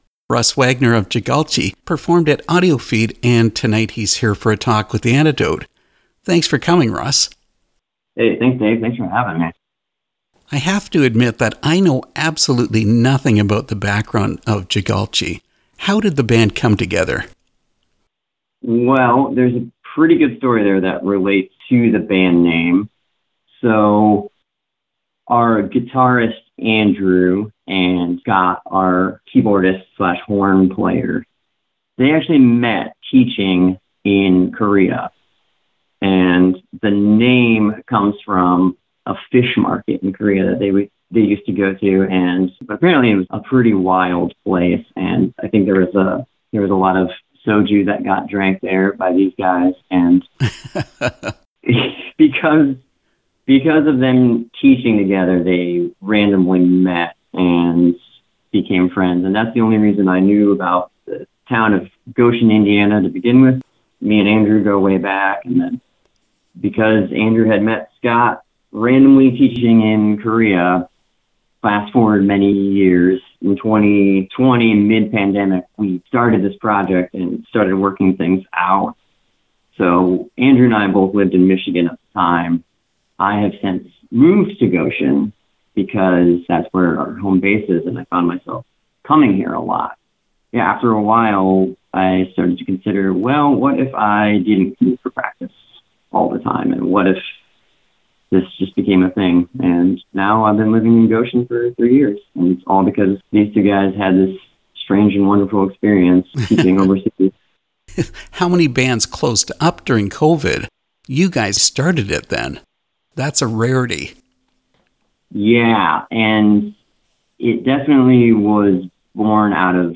Interview with JAGALCHI